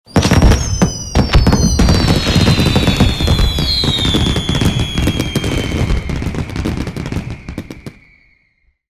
- fanfare.mp3 - winner celebration sound